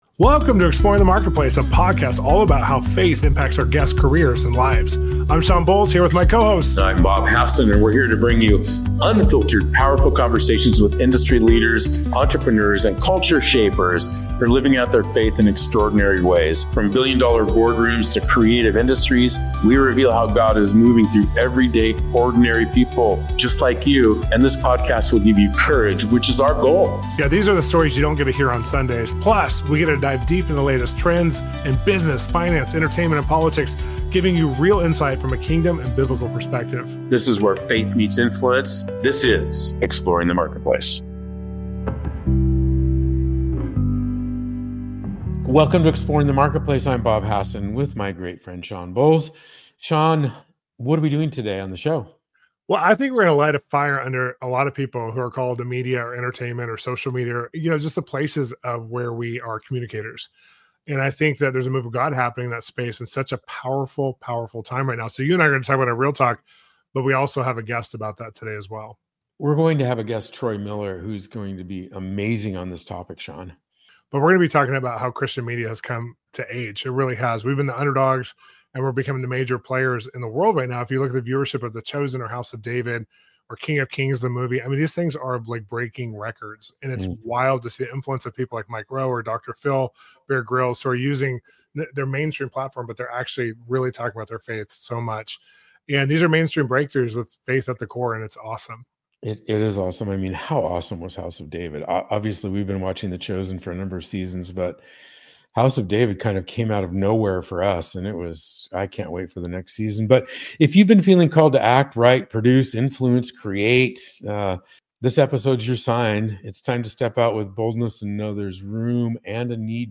Come join the conversation!